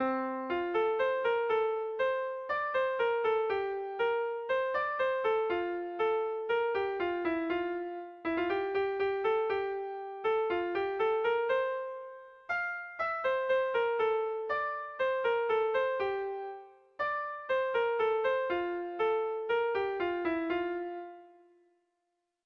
Estructura musical
ABDEF